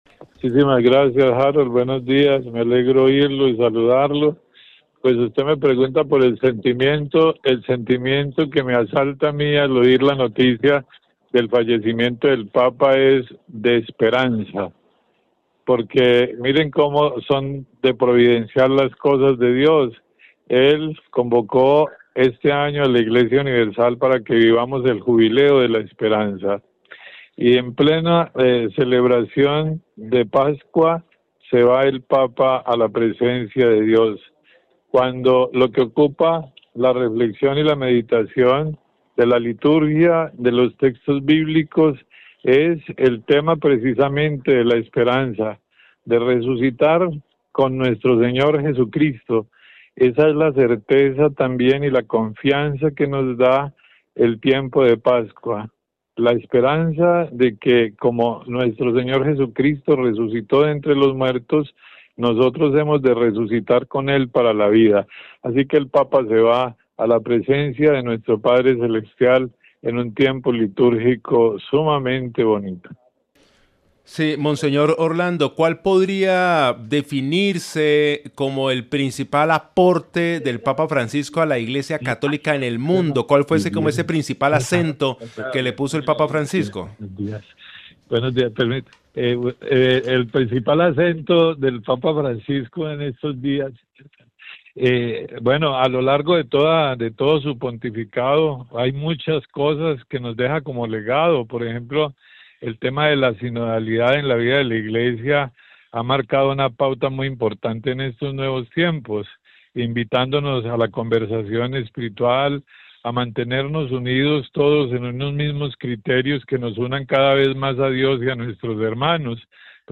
Reviva la entrevista completa con el arzobispo de Ibagué, monseñor Orlando Roa Barbosa sobre la muerte del Sumo Pontífice Francisco.